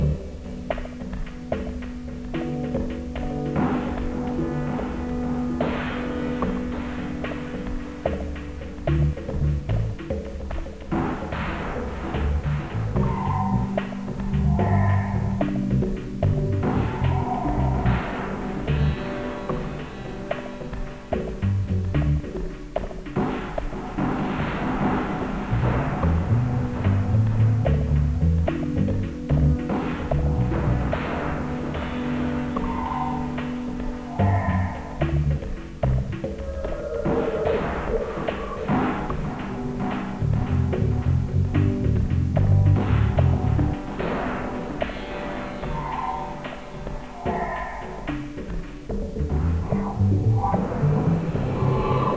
Type BGM
Speed 60%